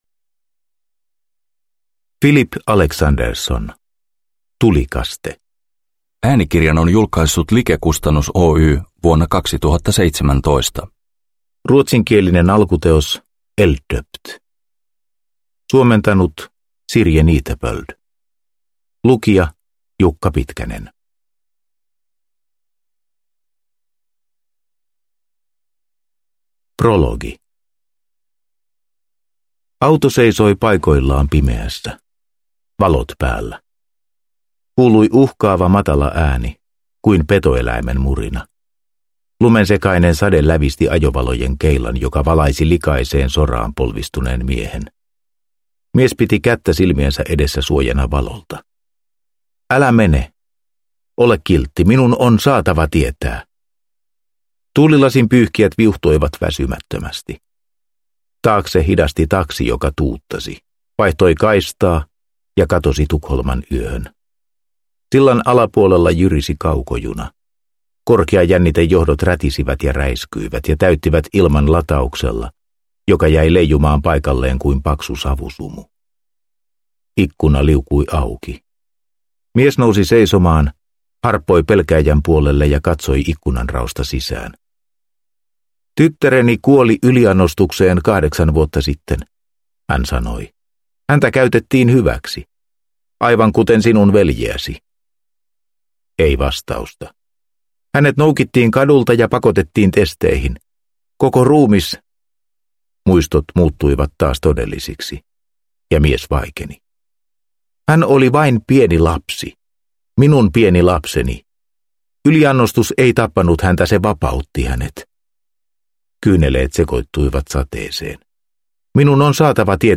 Tulikaste – Ljudbok – Laddas ner